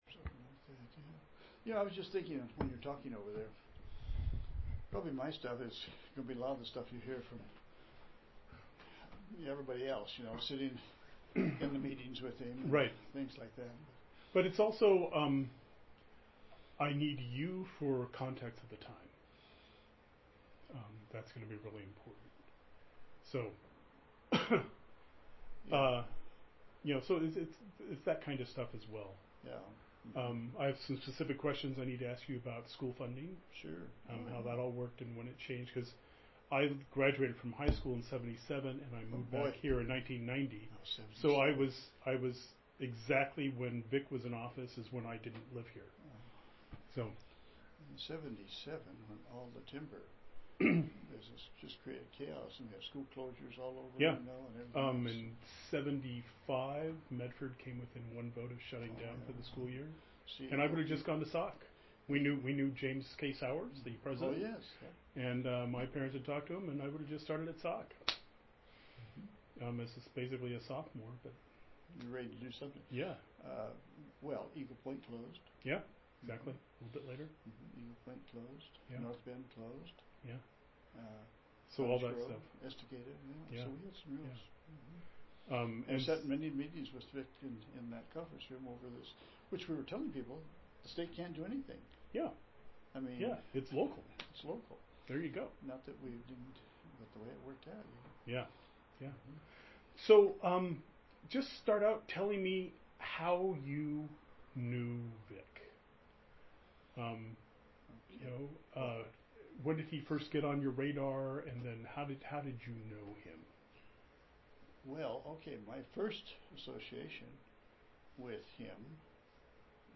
5cdaae3f5f3c83f18f461268b385c4b6f7753bcd.mp3 Title Verne Duncan interview on Atiyeh Description An interview of Verne Duncan on the topic of Oregon Governor Vic Atiyeh, recorded on August 12, 2014. Duncan served during Atiyeh's administration as Oregon's Superintendent of Public Instruction from 1975-1989.